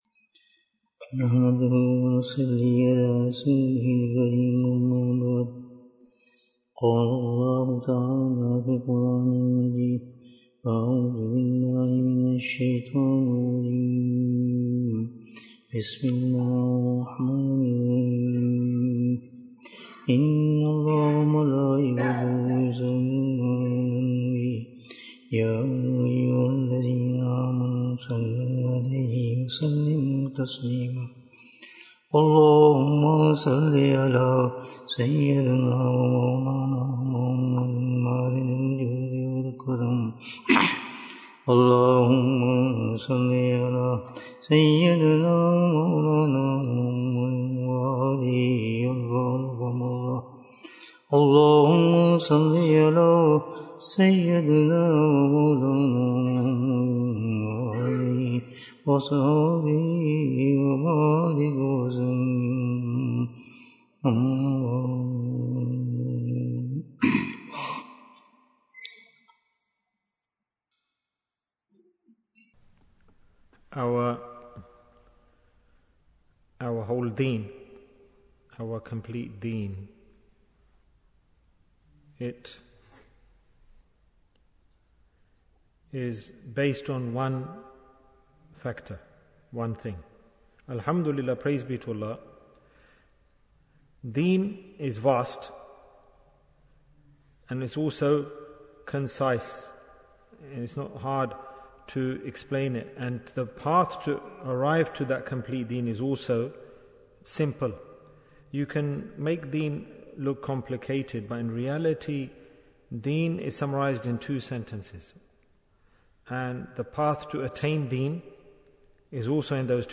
The Strong Bond With Allah Bayan, 34 minutes18th May, 2023